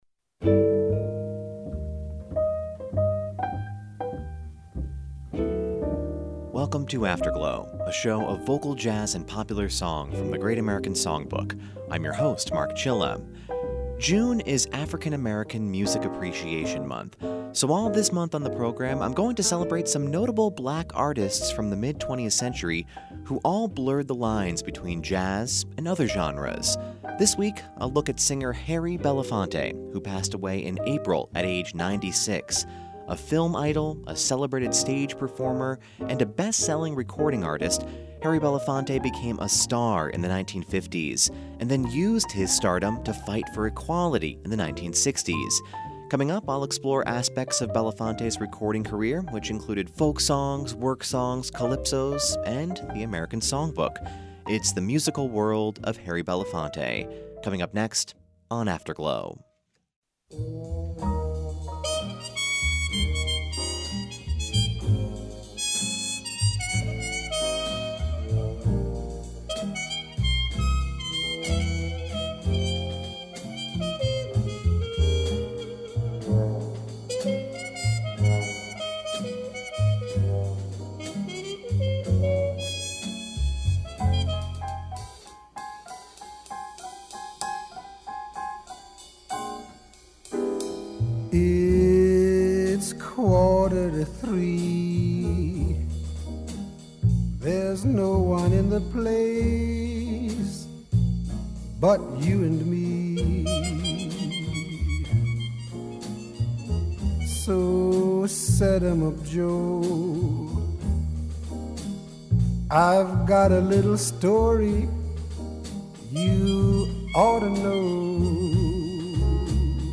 Welcome to Afterglow, a show of vocal jazz and popular song from the Great American Songbook